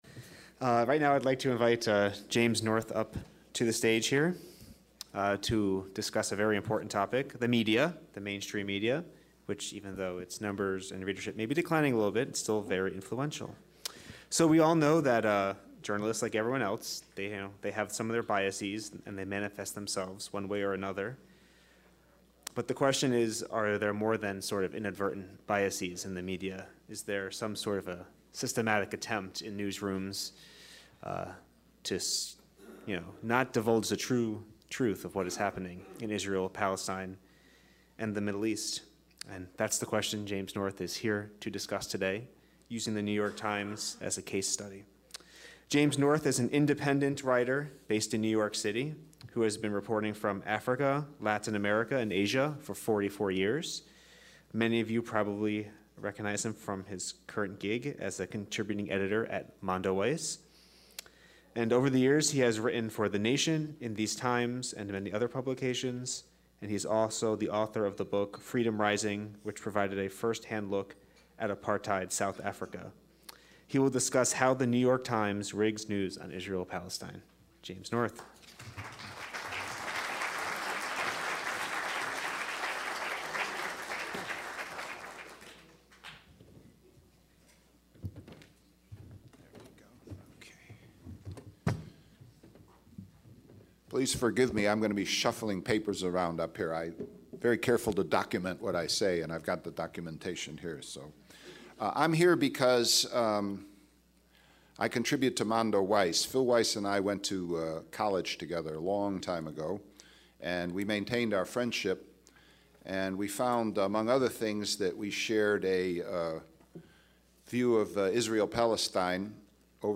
The Israel Lobby and American Policy Conference, March 2, 2018, National Press Club, Washington, DC